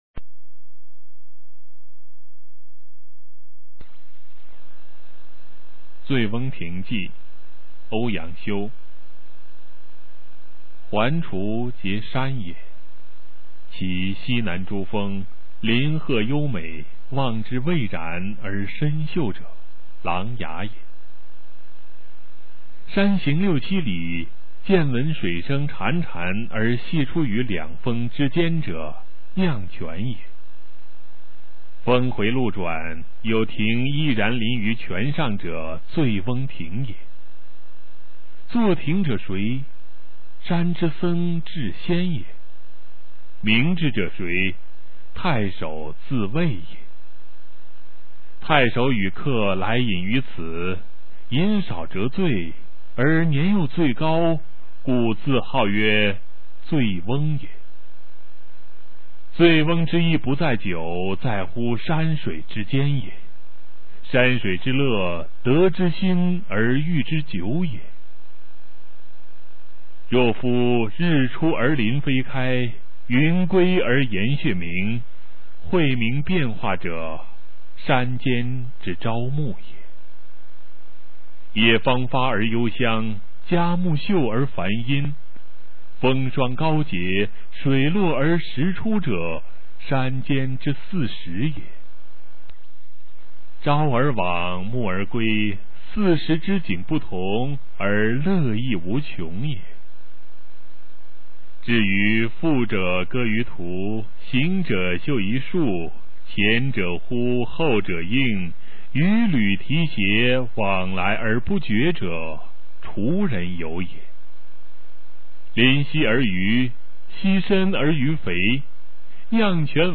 《醉翁亭记》原文与译文（含在线朗读）
语文教材文言诗文翻译与朗诵 初中语文九年级上册 目录